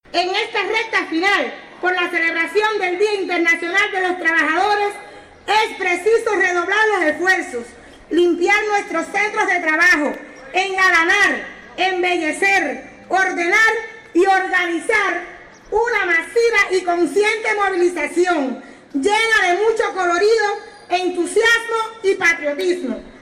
Un matutino especial se efectuó esta mañana en la sede del PCC Provincial con motivo del otorgamiento a Granma de la…